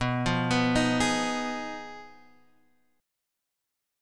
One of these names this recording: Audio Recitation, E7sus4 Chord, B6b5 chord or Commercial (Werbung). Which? B6b5 chord